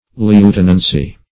Lieutenancy \Lieu*ten"an*cy\ (l[-u]*t[e^]n"an*s[y^]; 277), n.